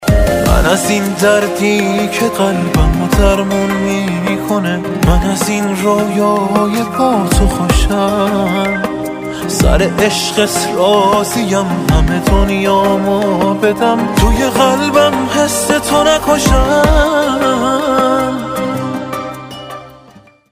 زنگ خور موبایل احساسی